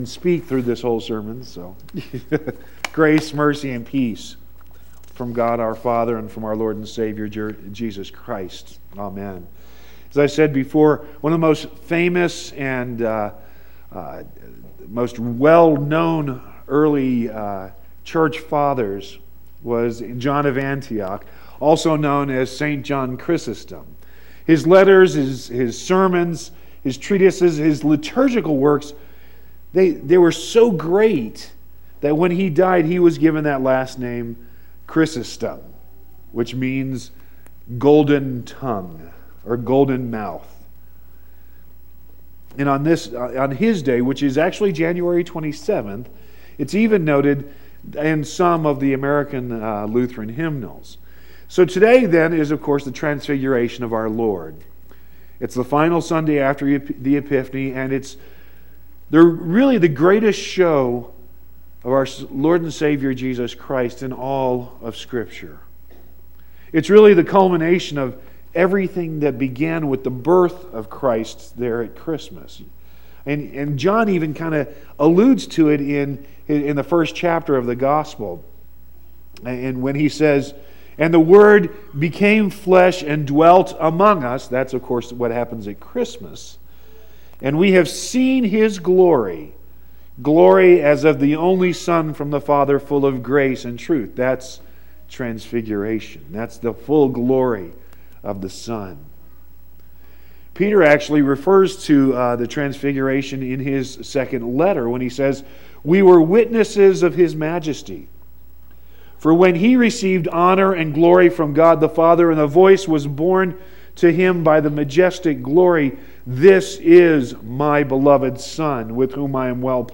2-11-18-sermon.mp3